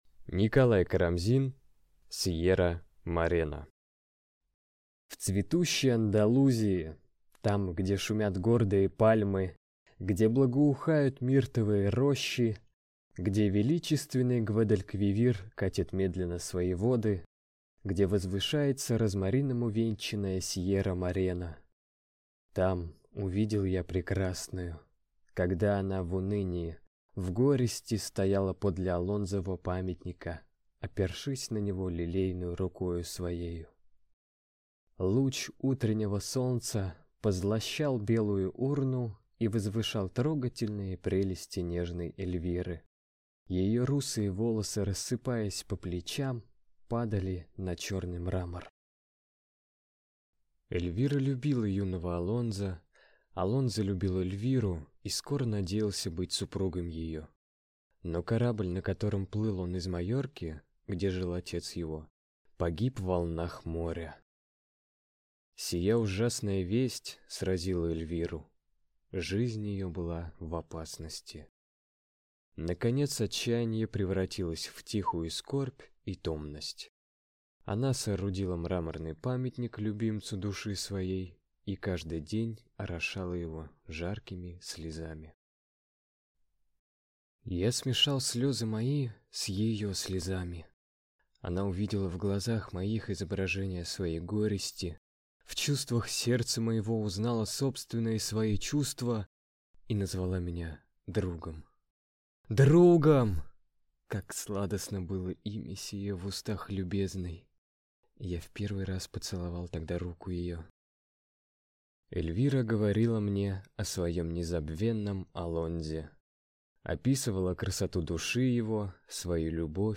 Аудиокнига Сиерра-Морена | Библиотека аудиокниг